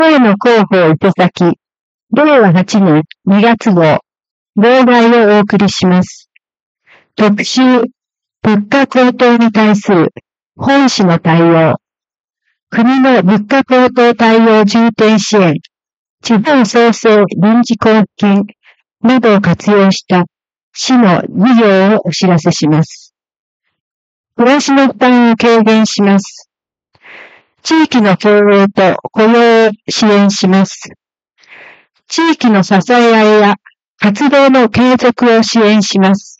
声の広報は目の不自由な人などのために、「広報いせさき」を読み上げたものです。
朗読
伊勢崎朗読奉仕会